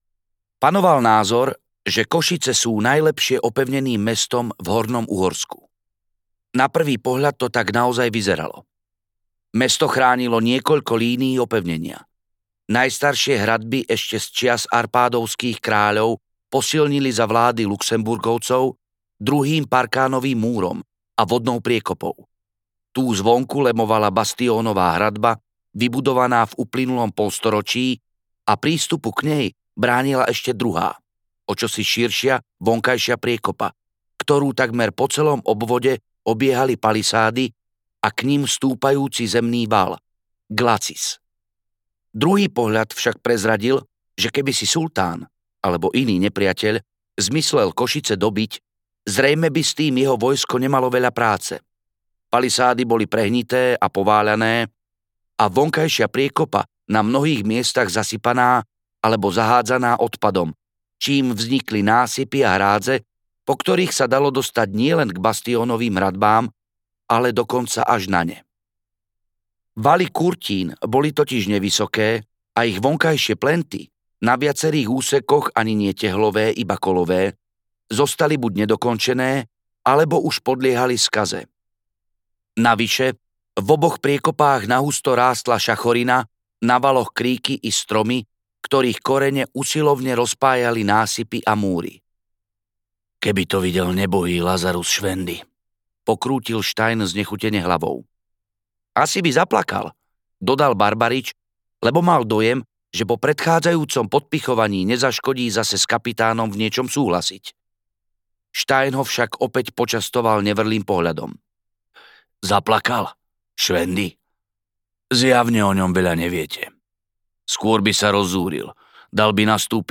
Prekliata kniha audiokniha
Ukázka z knihy